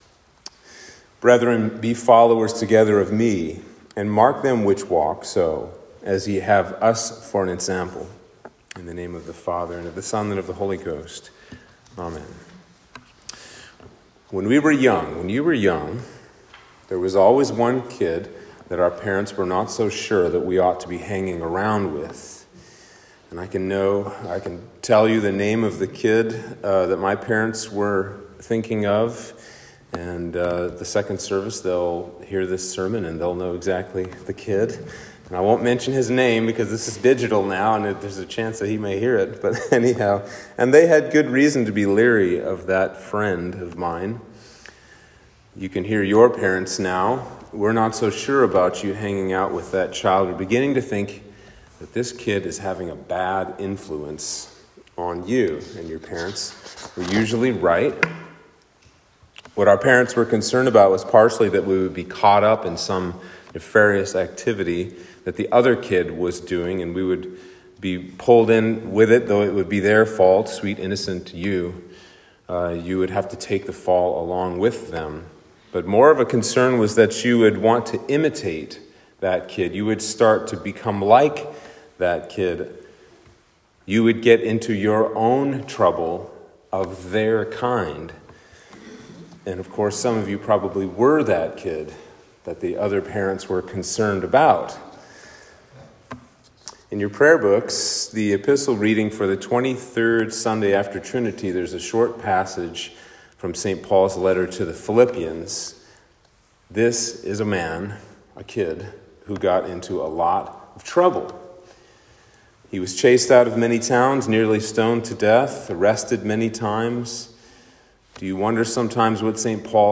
Sermon for Trinity 23